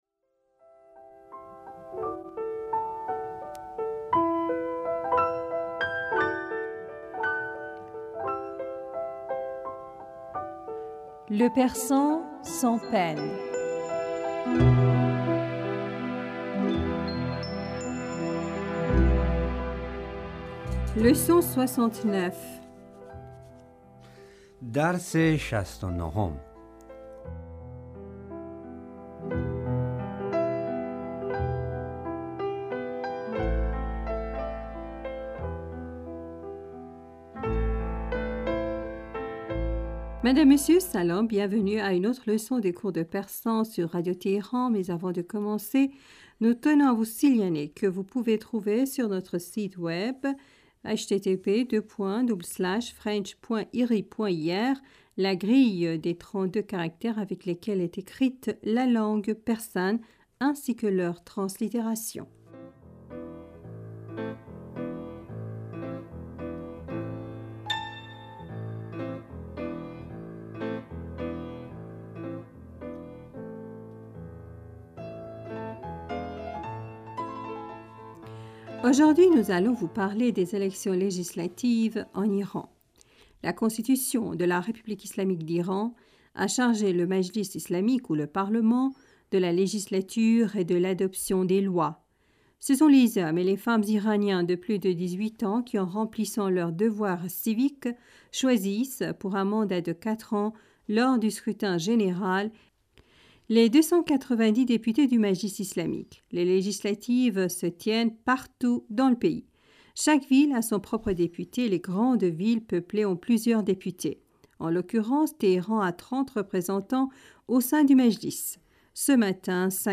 Ecoutez et répétez après nous.